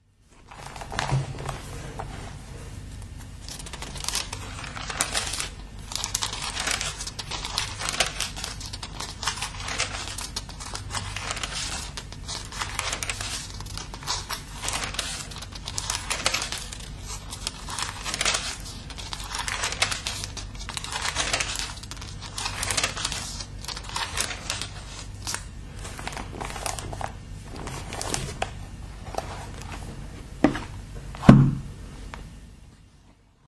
描述：翻开教会在1942年送给我父亲的《圣经》（荷兰语译本）中的法官书页。